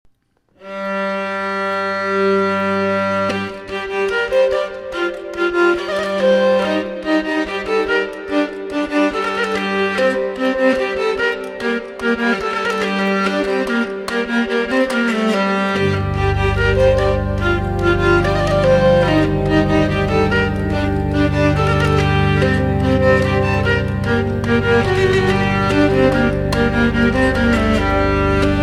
Zanfoña (audio/mpeg)
Consta de una manivela que hace girar una rueda, la cual engancha las cuerdas gracias a una resina y las hace vibrar. También tiene un teclado mediante el cual se presionan las cuerdas para tocar las notas.